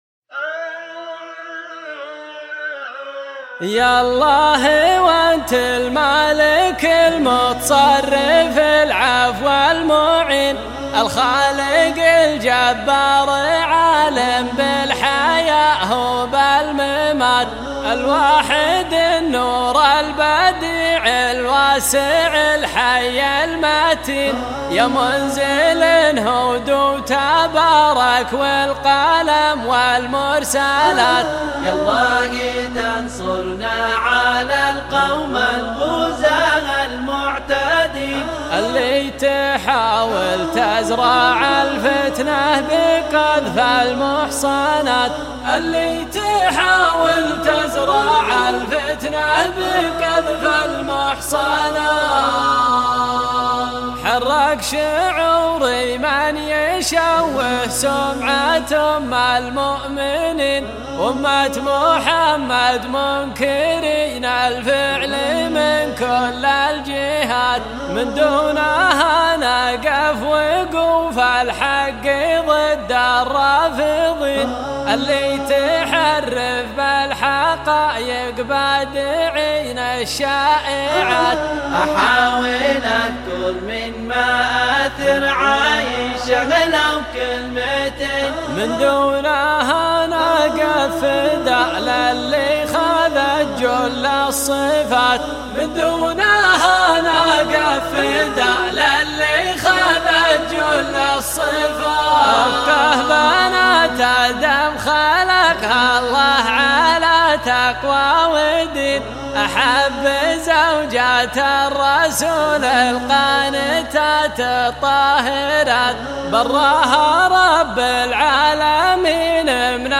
انشاد